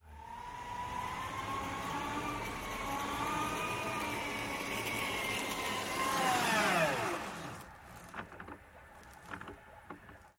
Звук движения на двухколесном электросамокате